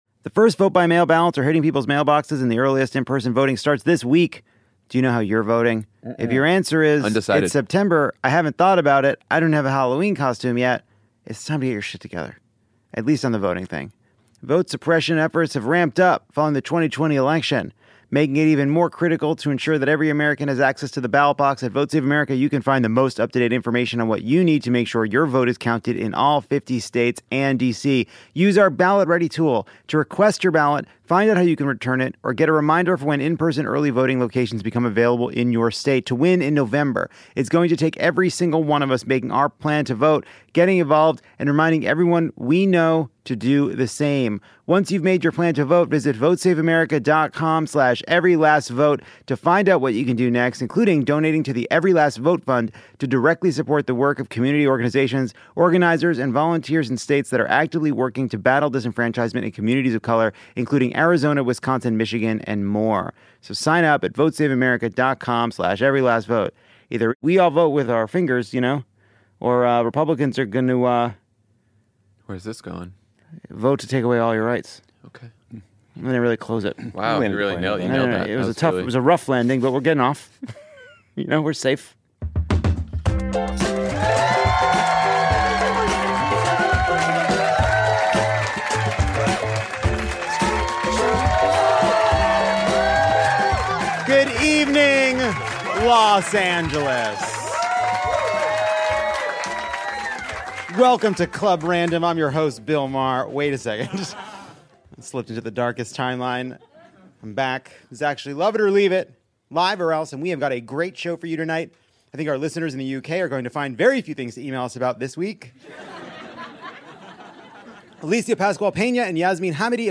Lovett or Leave It finds a way to have yet another excellent show at Los Angeles’ gorgeous Dynasty Typewriter theater.